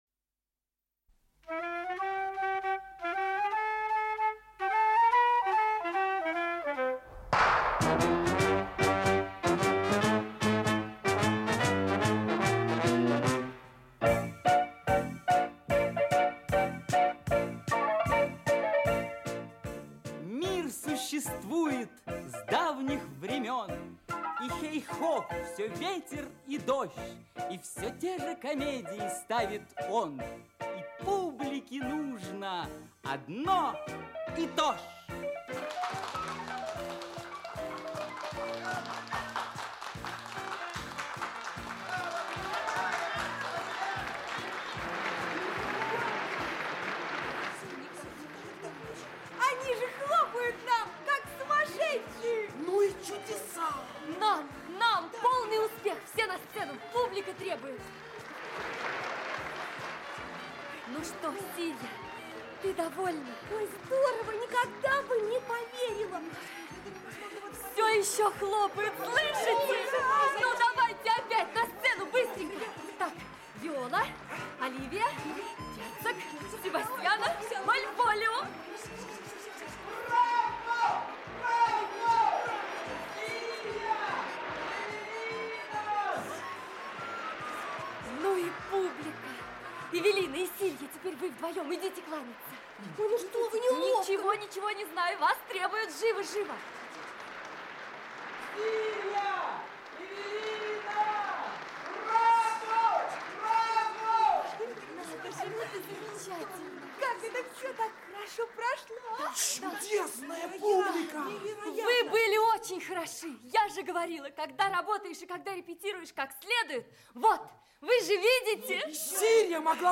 Aудиокнига В канун Нового года Автор Рауха Виртанен Читает аудиокнигу Актерский коллектив.